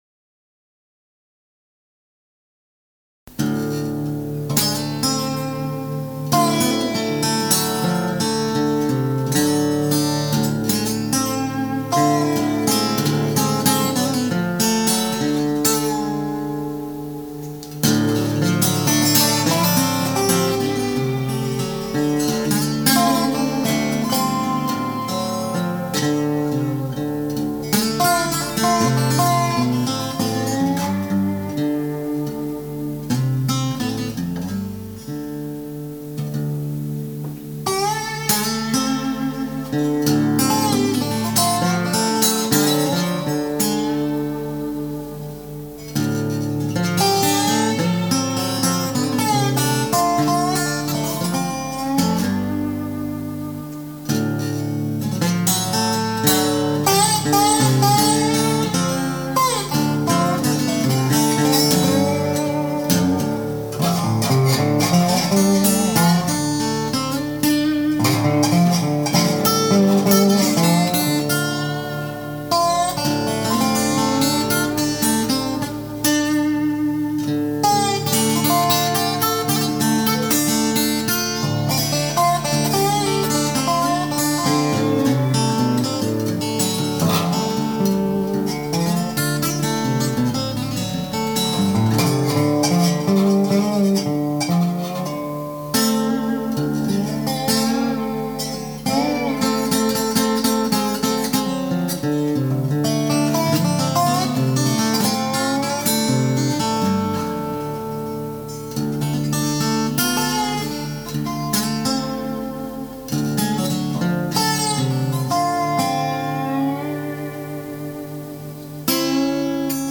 A blues-ish improvisation on acoustic guitar to which I added a slide guitar part.
Instrumental